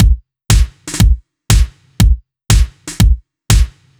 Index of /musicradar/french-house-chillout-samples/120bpm/Beats
FHC_BeatA_120-02_KickSnare.wav